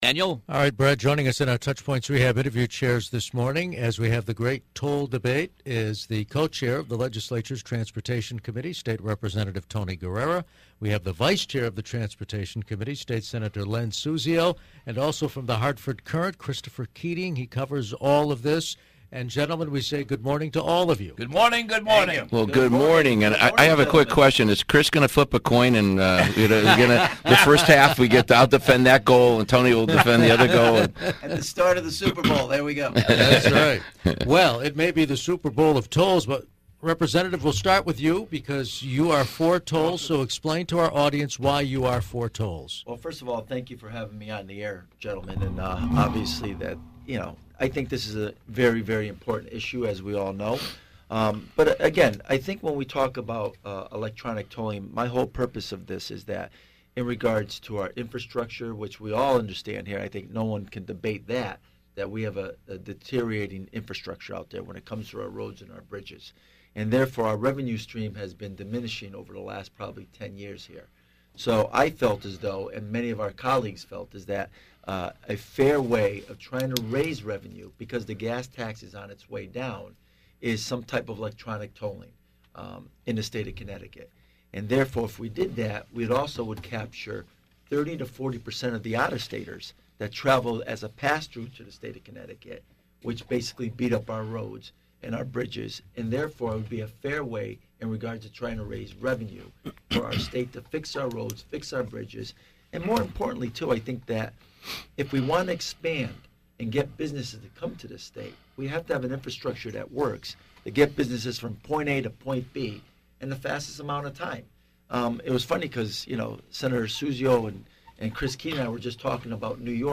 Supporting tolls was state Rep. Tony Guerrera, a Democrat who is co-chair of the General Assembly's Transportation Committee. Opposing tolls was state Sen. Len Suzio, a Republican who is vice-chair of the committee.